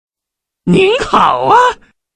变态男声你好啊音效_人物音效音效配乐_免费素材下载_提案神器